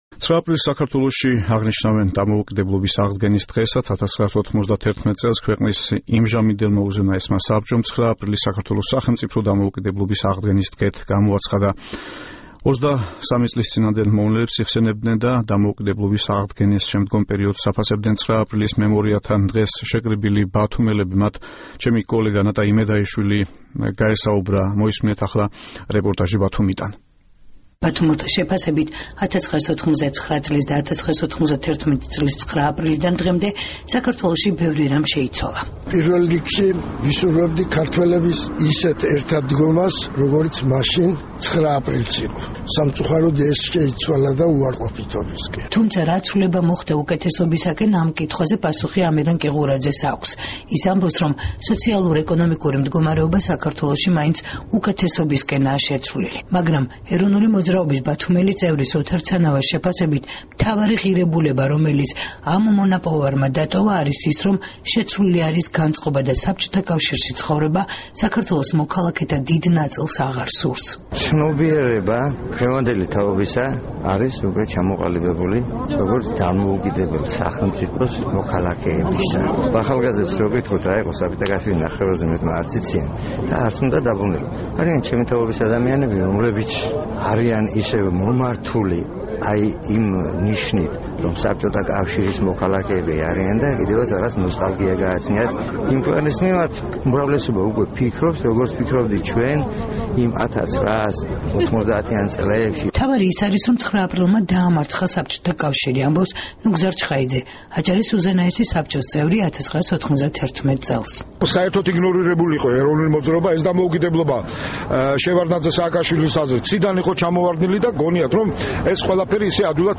9 აპრილს საქართველოში დამოუკიდებლობის აღდგენის დღესაც აღნიშნავენ. 1991 წელს ქვეყნის იმჟამინდელმა უზენაესმა საბჭომ 9 აპრილი საქართველოს სახელმწიფოებრივი დამოუკიდებლობის აღდგენის დღედ გამოაცხადა. 23 წლის წინანდელ მოვლენებს იხსენებდნენ და დამოუკიდებლობის აღდგენის შემდგომ პერიოდს აფასებდნენ 9 აპრილის მემორიალთან დღეს შეკრებილი ბათუმელები.